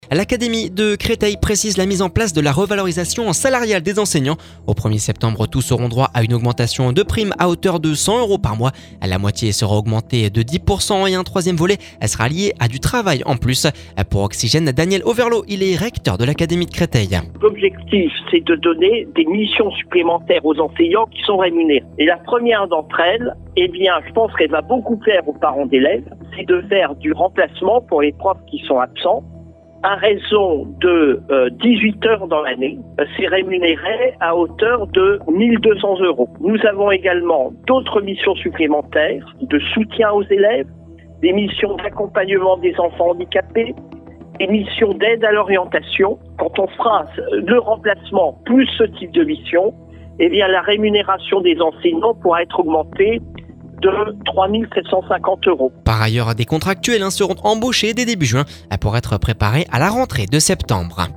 Pour Oxygène Daniel Auverlot, recteur de l'Académie de Créteil.